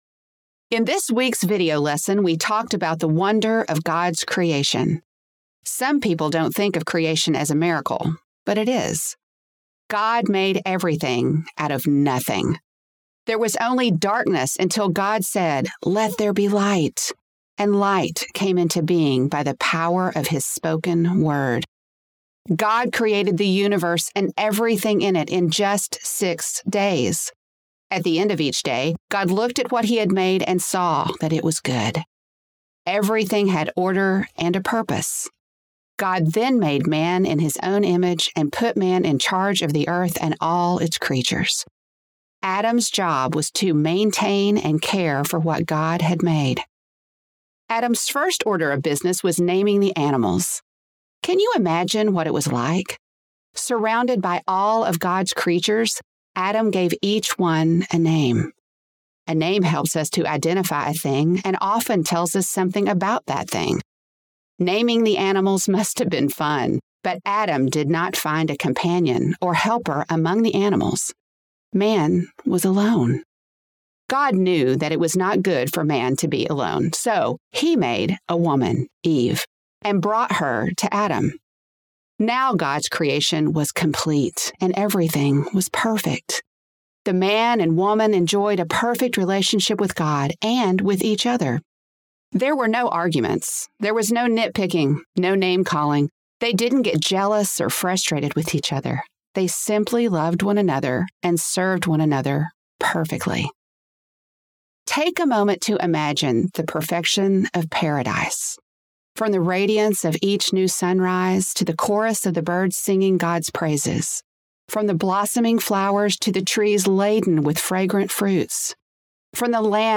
The Word in Motion Old Testament MP3 Audiobook Download
Preview Samples